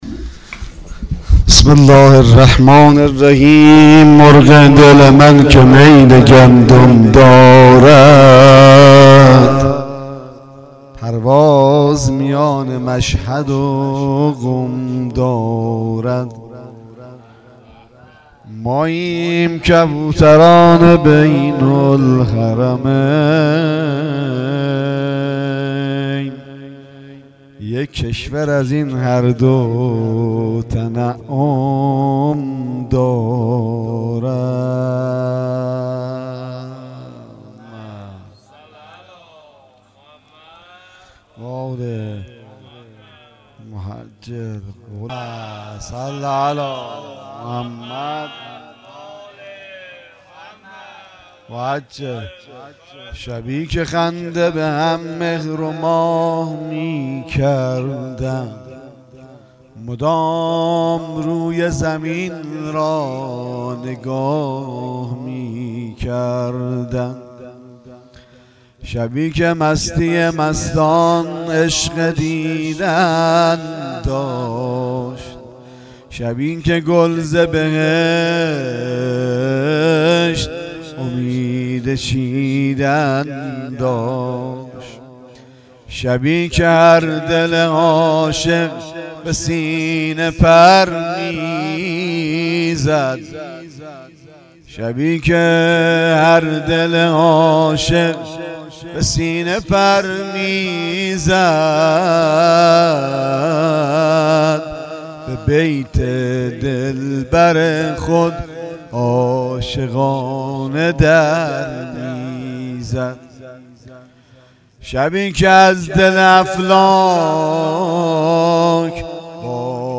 مدح سرود